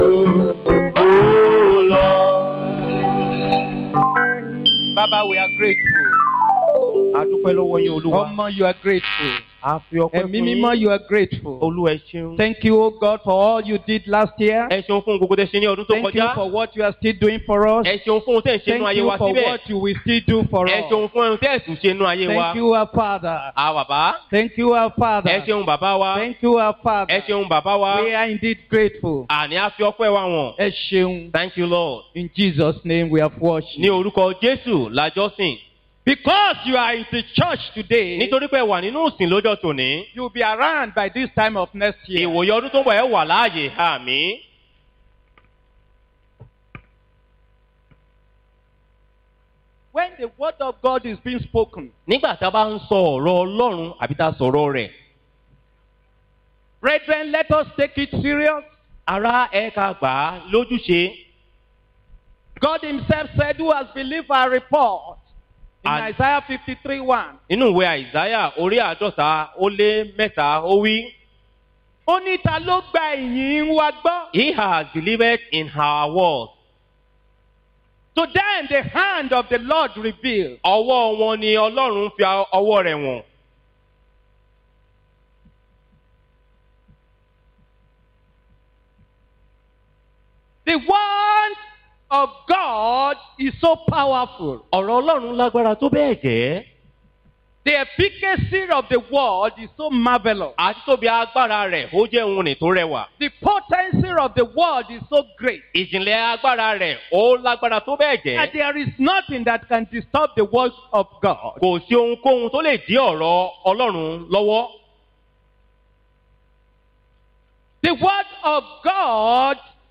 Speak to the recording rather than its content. Posted in Sunday Service